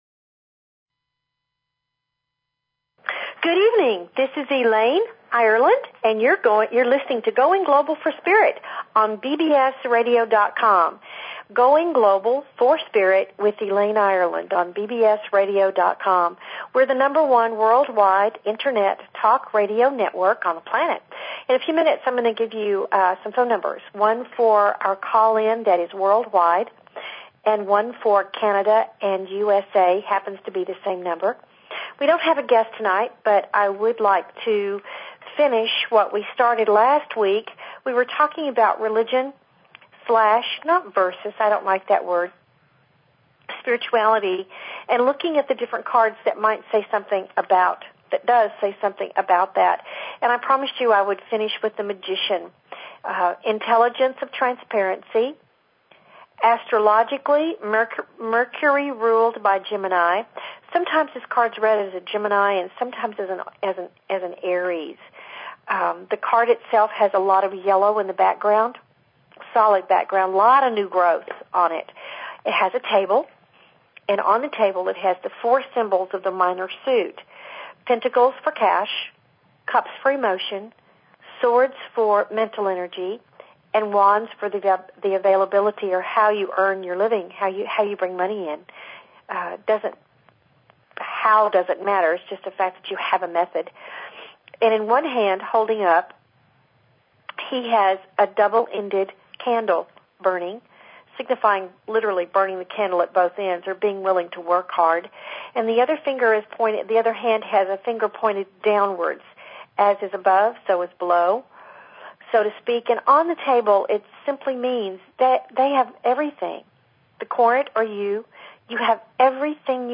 OPEN MIKE NIGHT, SO TO SPEAK...
They invite you to call in with your questions and comments about everything metaphysical and spiritual!"